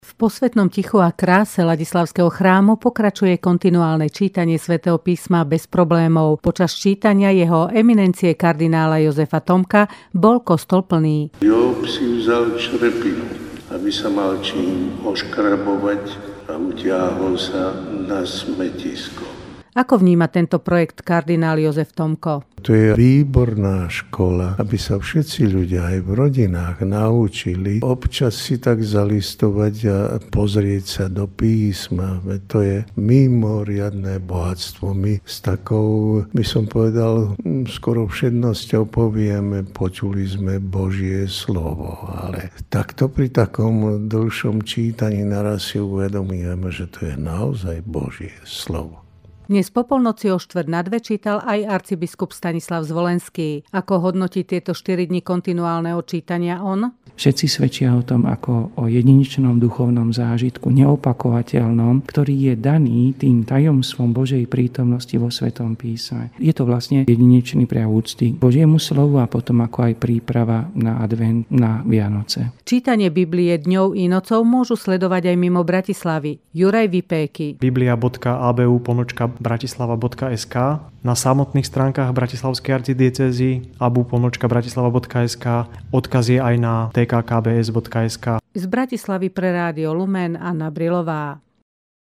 Rádio Lumen   Príspevok v rámci Infolumenu Rádia Lumen: